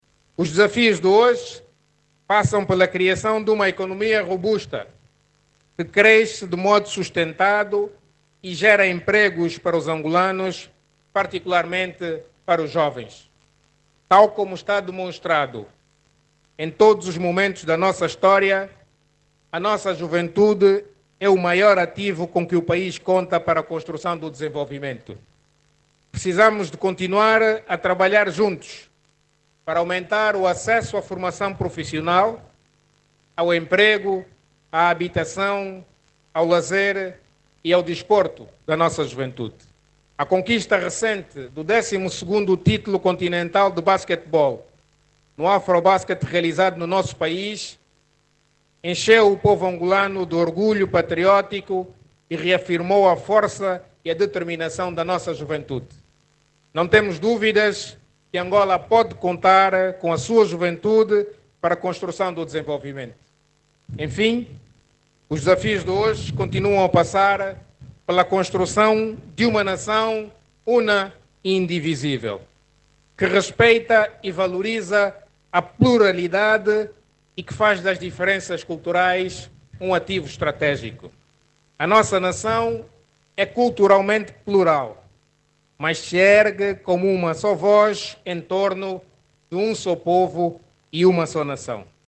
O Ministro de Estado e Chefe da Casa Civil do Presidente da República, diz que o país conta com os jovens para construir a Angola que queremos. Adão de Almeida que falava no acto comemorativo dos 103 anos do primeiro Presidente Agostinho Neto, diz que em todos os momentos da vida do país os jovens tomaram a dianteira e assumiram o papel crucial.